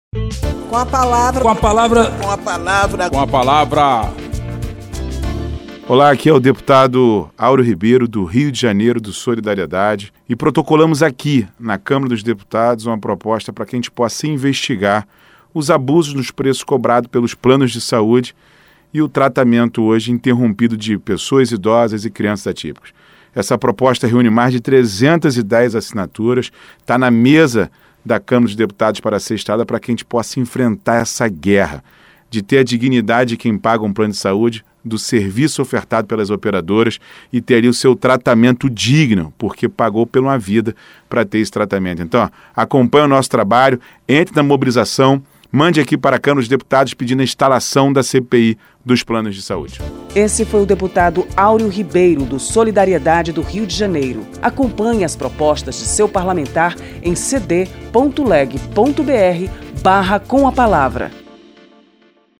O deputado Aureo Ribeiro (Solidariedade-RJ) defendeu a urgente criação de uma Comissão Parlamentar de Inquérito (CPI) para investigar os abusivos aumentos dos planos de saúde e a interrupção de tratamento de doenças de pessoas idosas e atípicas.
Espaço aberto para que cada parlamentar apresente aos ouvintes suas propostas legislativas